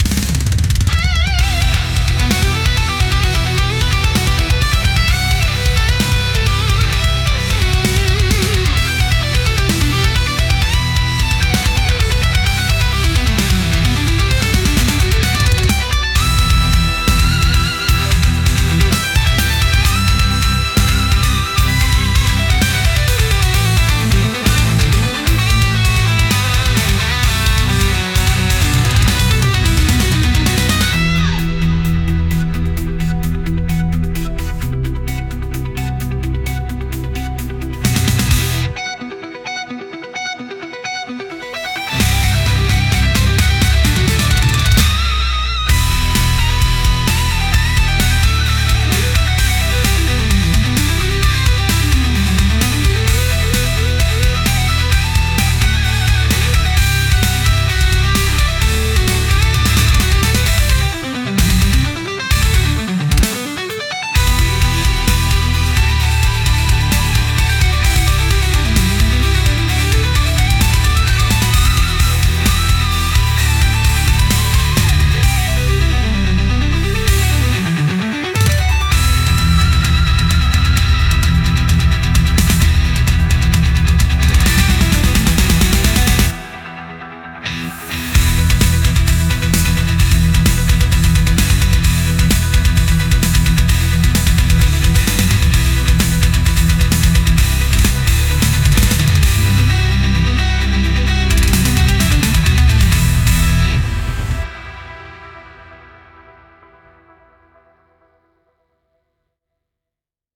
Genre: Rock Mood: Aggressive Editor's Choice